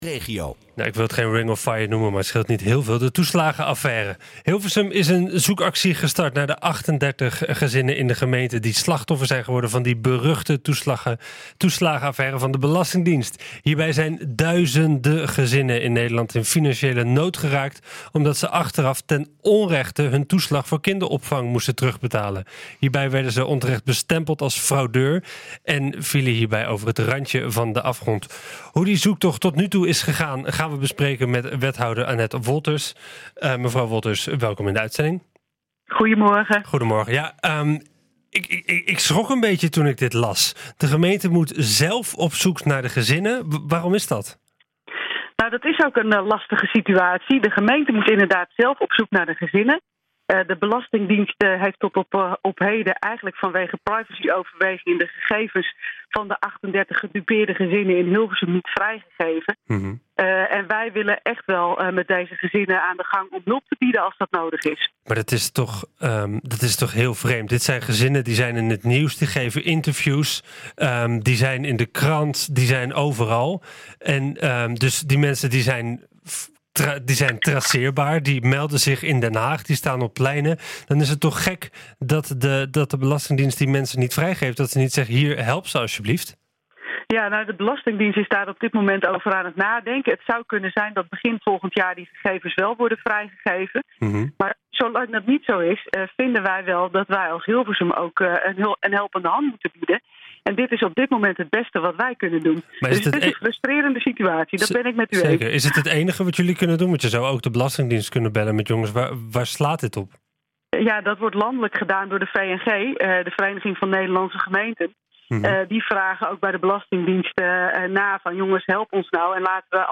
"Het is enorm frustrerend", zegt wethouder Annette Wolthers het in het radioprogramma NH Gooi Zaterdag.